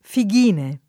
figg&ne] e Fegghine [fegg&ne] (sia l’una sia l’altra, secondo i vari manoscritti, in un passo di Dante), nonché Fighine [fig&ne], forma der. al pari delle altre dal lat. figulus [